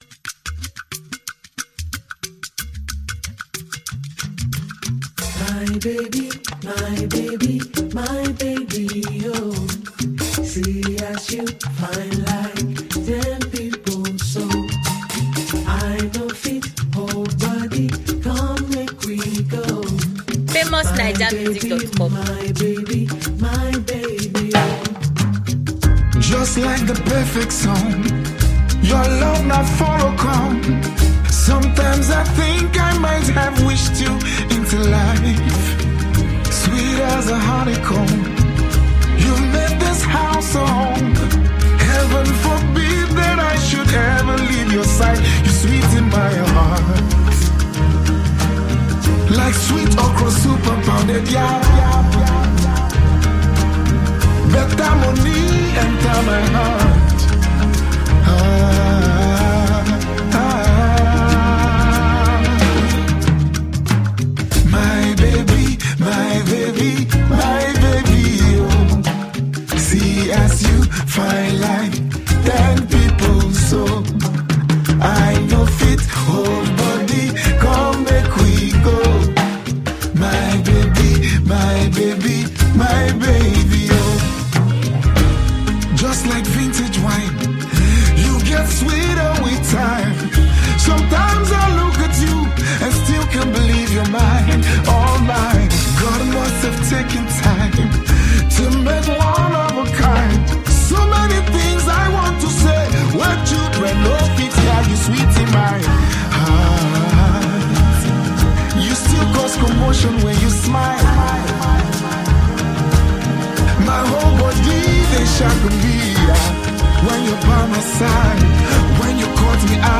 the track brings a smooth mix of deep tunes